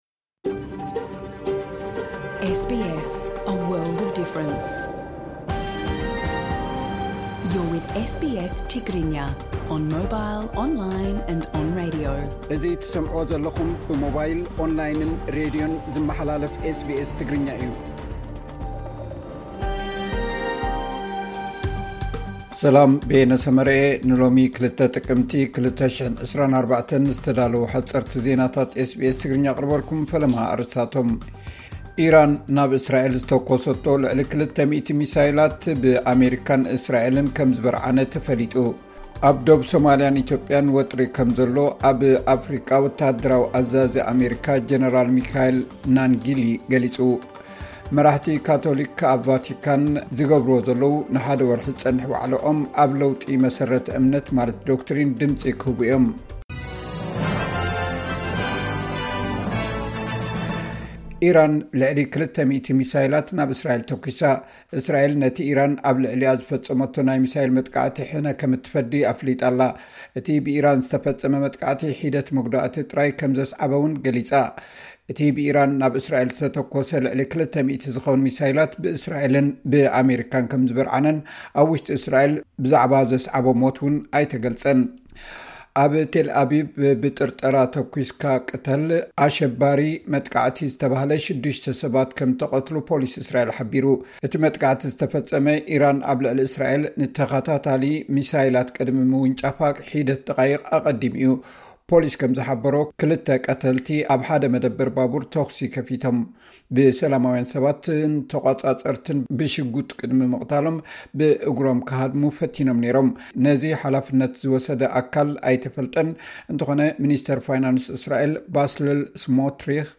ሓጸርቲ ዜናታት ኤስ ቢ ኤስ ትግርኛ (02 ጥቅምቲ 2024)